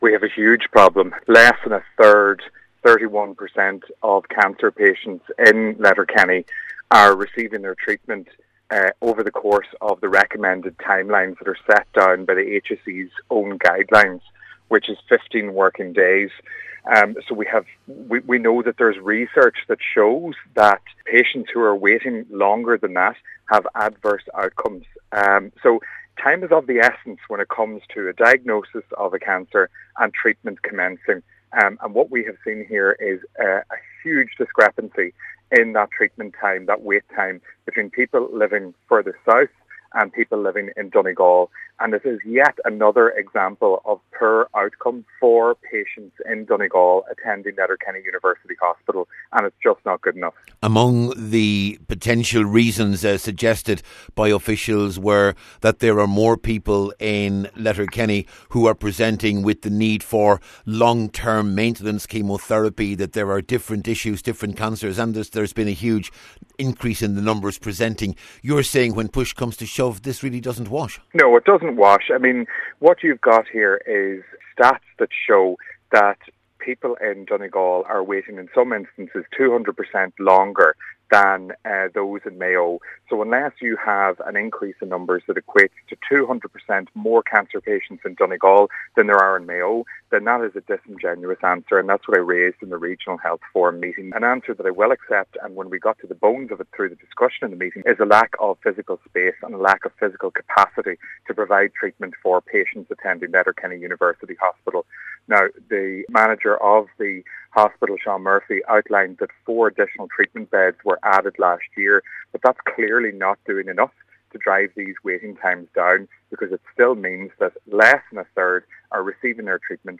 Cllr Meehan says that process must be speeded up……..